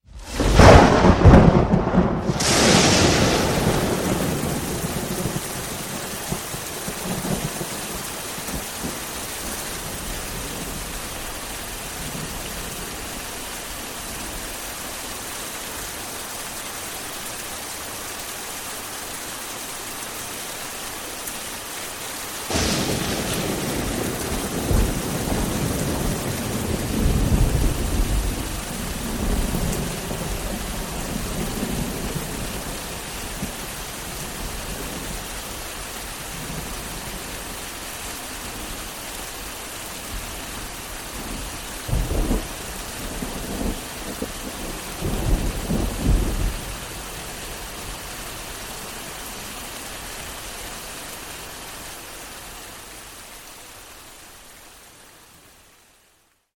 my second attempt at using layoring to create a fake storm